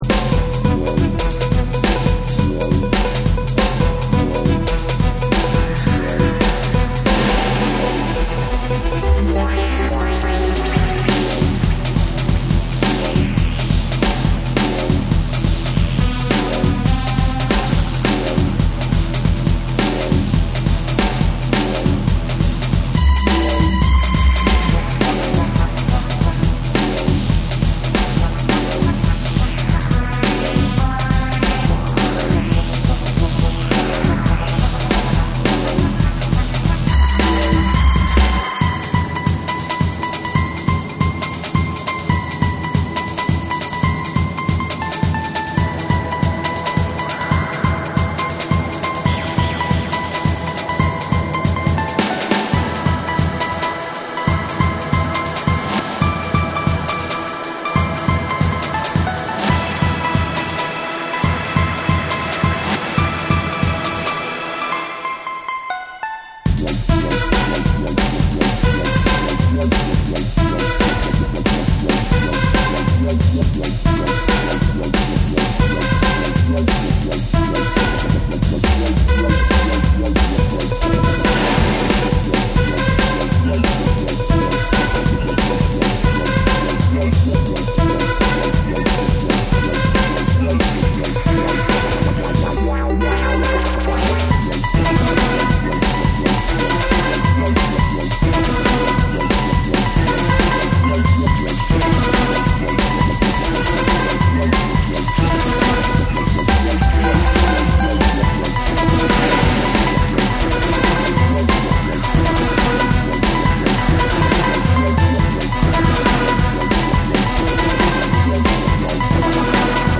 Dub ~ Modern Dancehall ~ Experimental
REGGAE & DUB / MIX TAPE